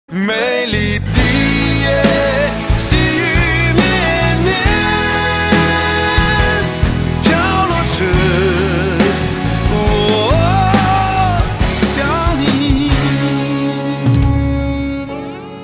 M4R铃声, MP3铃声, 华语歌曲 28 首发日期：2018-05-14 11:51 星期一